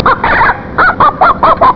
snd_16074_chicken.wav